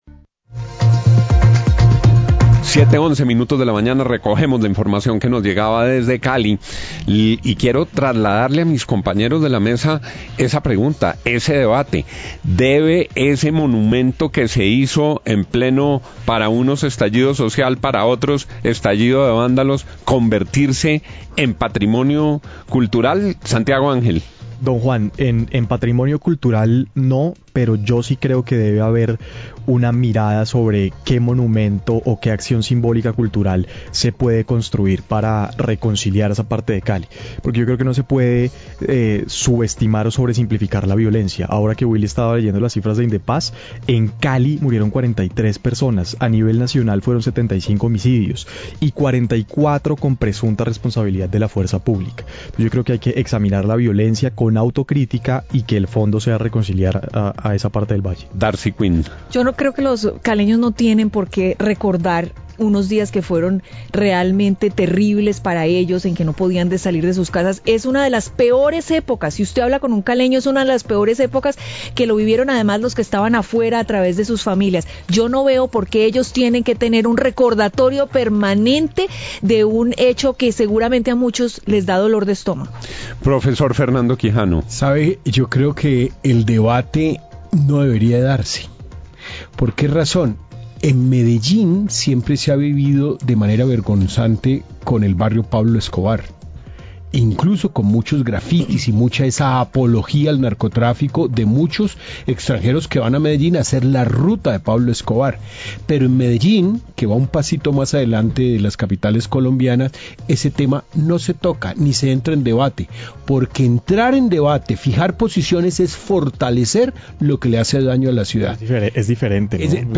Radio
La mesa de trabajo de La FM opina sobre la polémica por el anuncio del Ministro de Cultura para que el símbolo de la resistencia se convierta en patrimonio cultural.